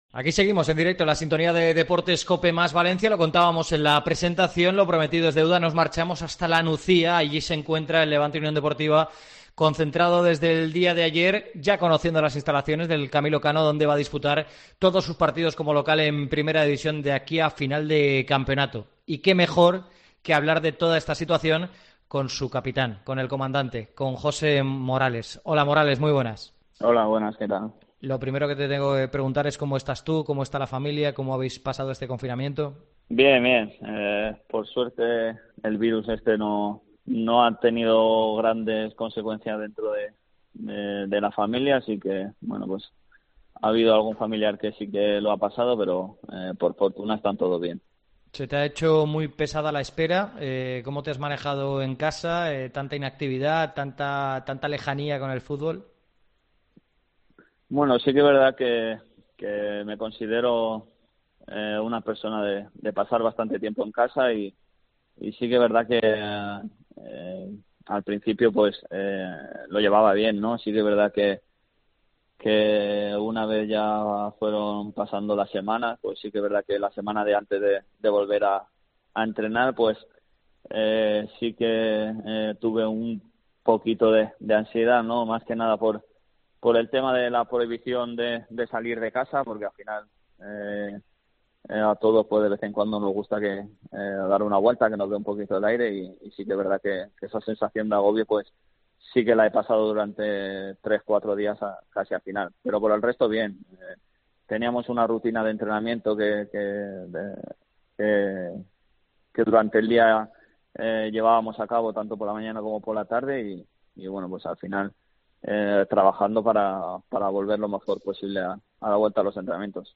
AUDIO. Entrevista COPE a Morales